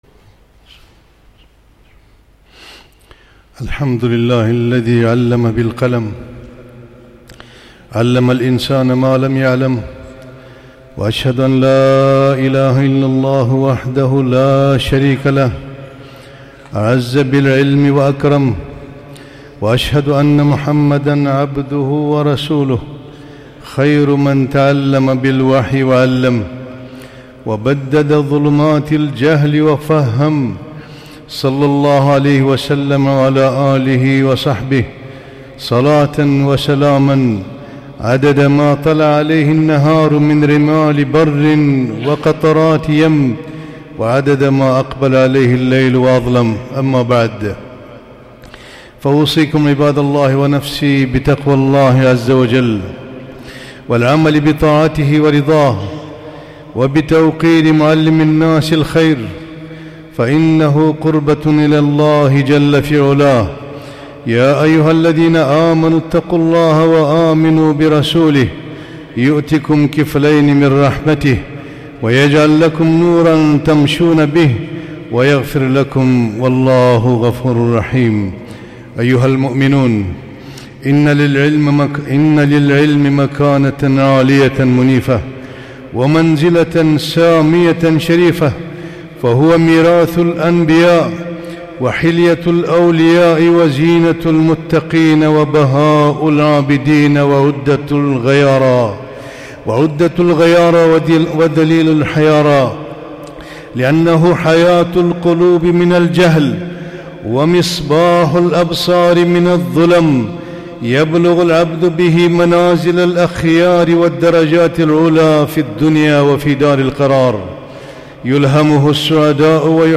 خطبة - آداب العلم والمتعلم